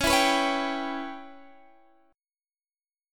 Dbm7b5 Chord
Listen to Dbm7b5 strummed